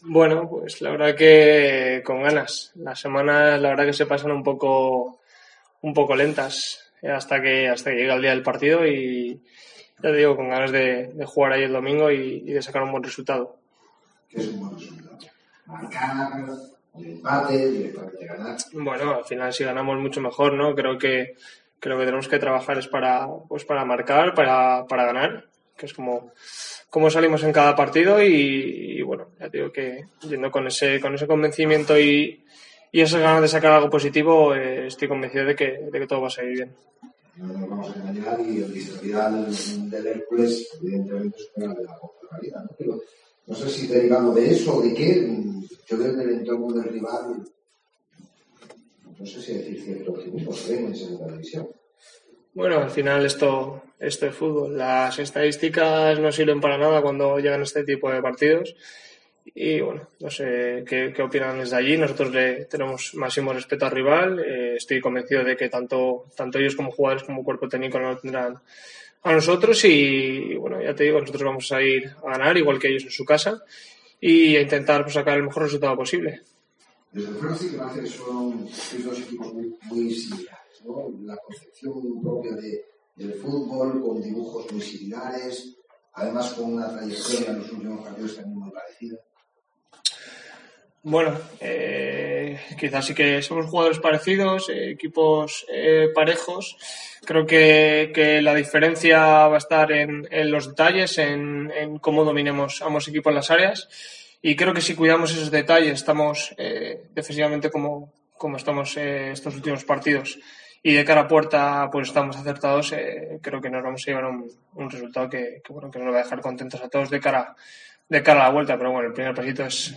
Escucha aquí las palabras del delantero de la Deportiva Ponferradina